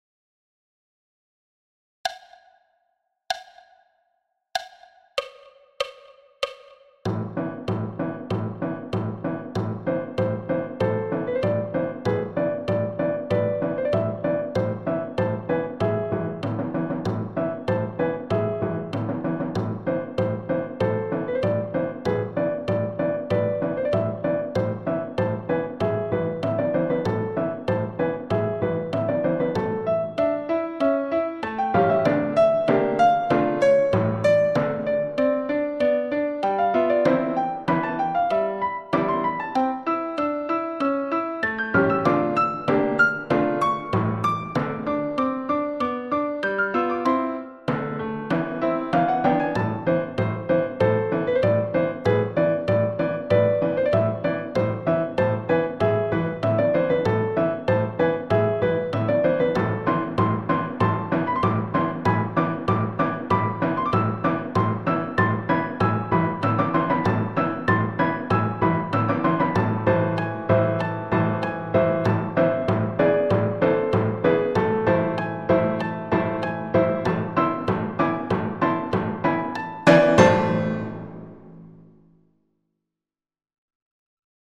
Le petit cygne – piano solo à 96 bpm
Le-petit-cygne-piano-solo-a-96-bpm.mp3